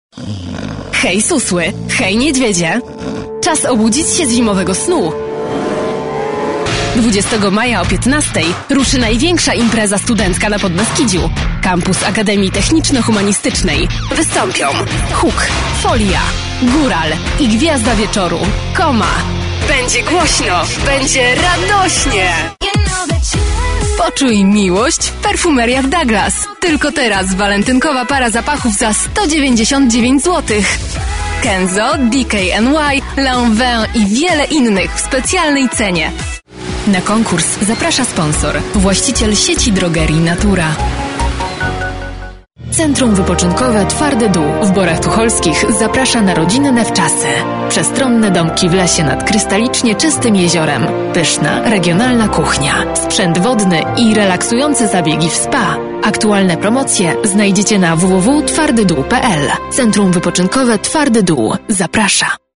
Female 20-30 lat
polski · Turnaround: 48h · Powitanie tel. Reklama Narracja do filmu
Nagranie lektorskie